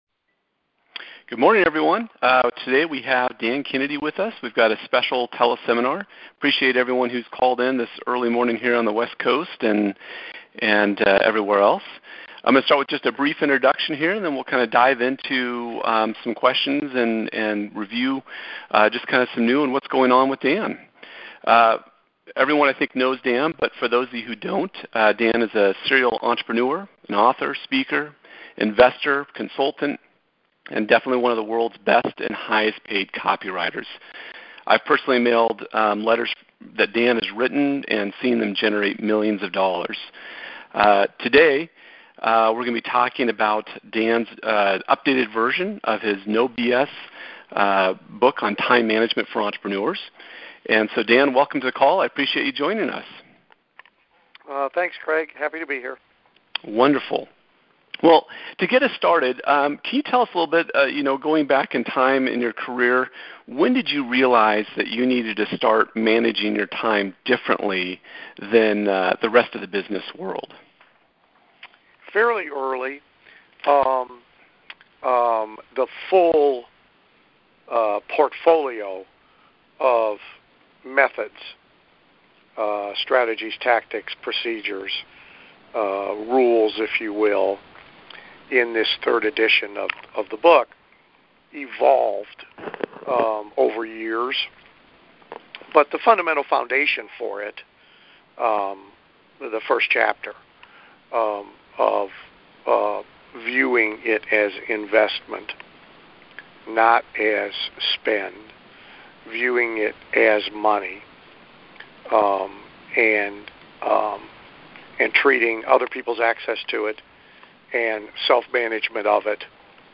Dan Kennedy Teleseminar